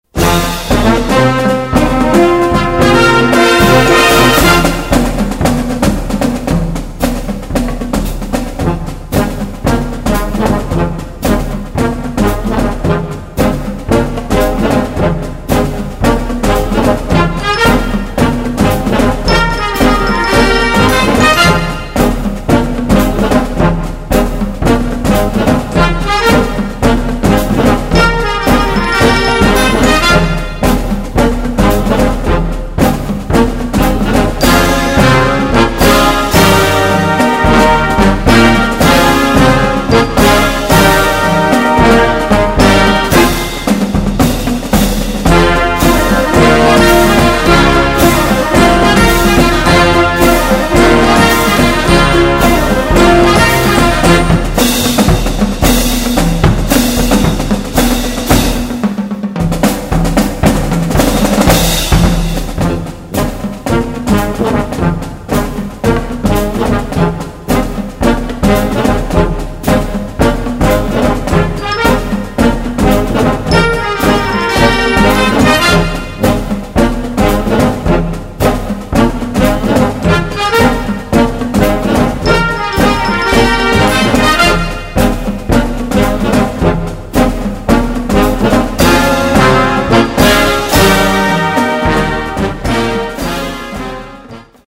Voicing: Marching Band